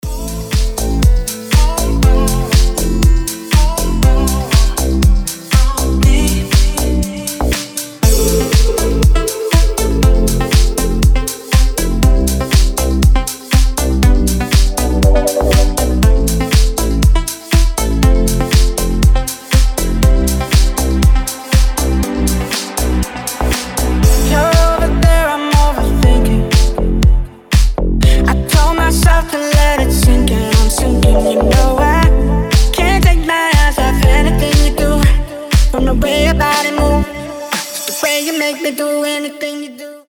• Качество: 320, Stereo
гитара
ритмичные
мужской вокал
deep house
мелодичные
Vocal House
Мелодичный deep, vocal house